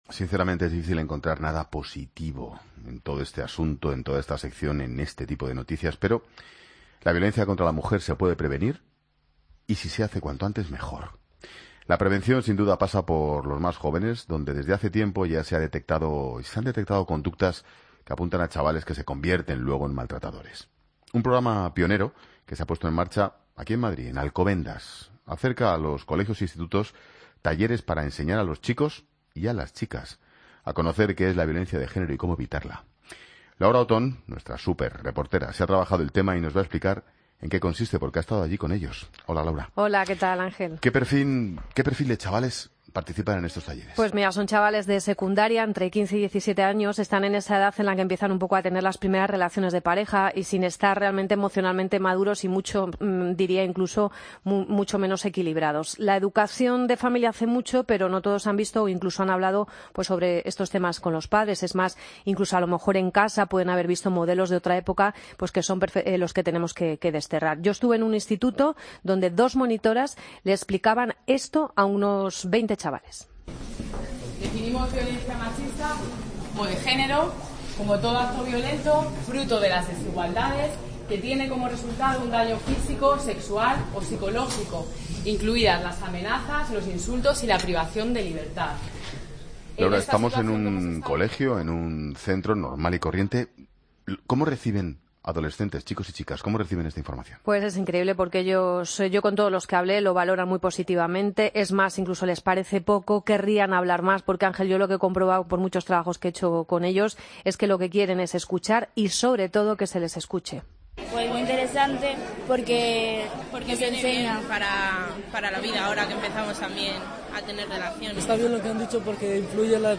reportaje 'Prevenir la violencia machista desde la escuela